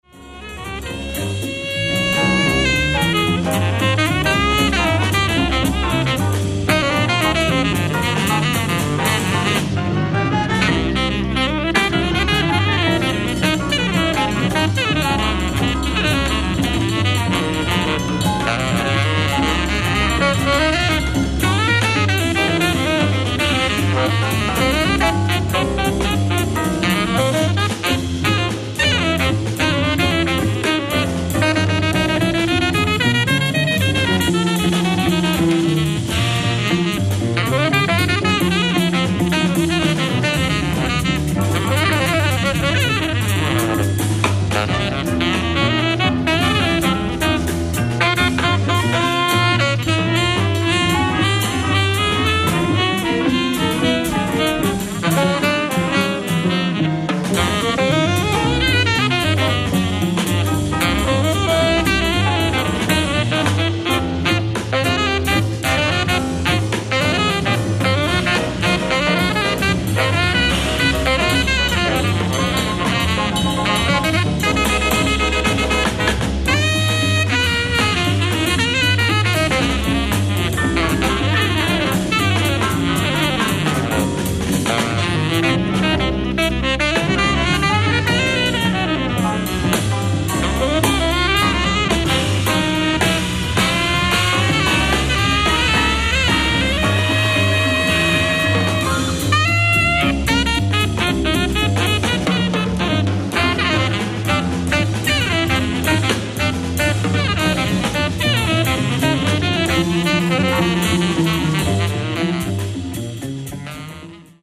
ライブ・アット・フィンランディア・ホール、ヘルシンキ、フィンランド 08/23/1976
※試聴用に実際より音質を落としています。